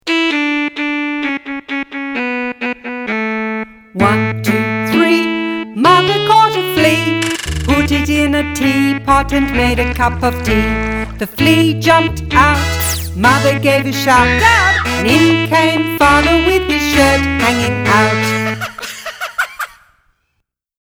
Silly song about a flea and teapot
(voc, fast)